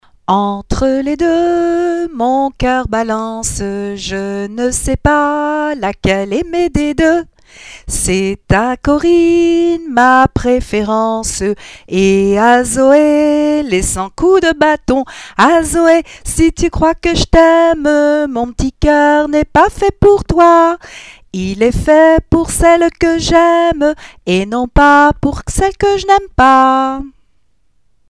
French Children's Songs